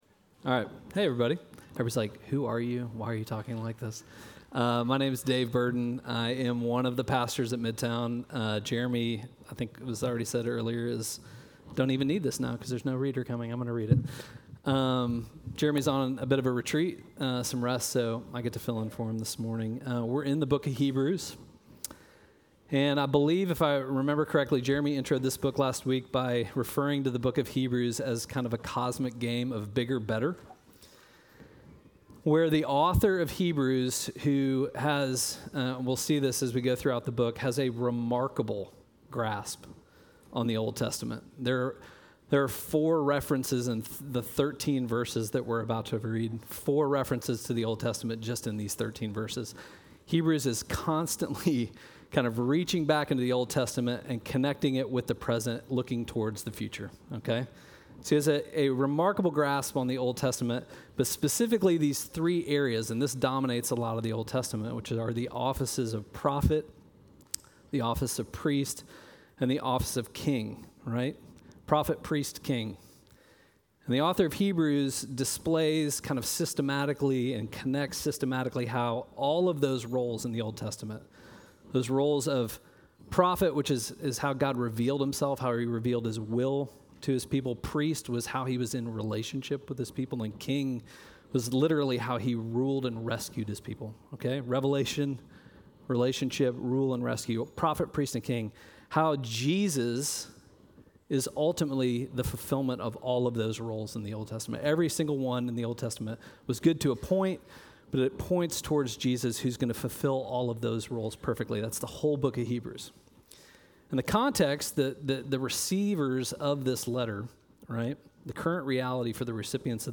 Midtown Fellowship Crieve Hall Sermons Restless Heart Syndrome Sep 21 2025 | 00:49:27 1x 00:00 / 00:49:27 Subscribe Share Apple Podcasts Spotify Overcast RSS Feed Share Link Embed